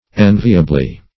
-- En"vi*a*ble*ness, n. -- En"vi*a*bly, adv.